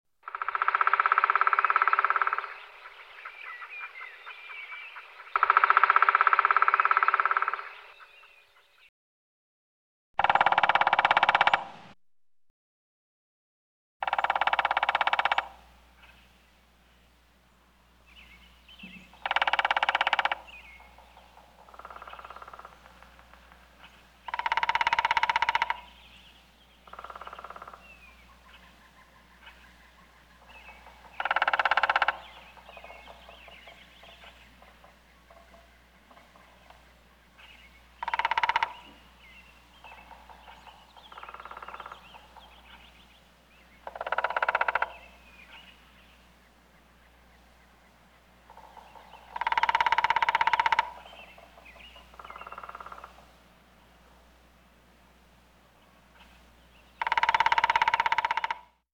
Ищу звук ДЯТЛА.
Помогите с поиском стуков дятла пожалуйста!....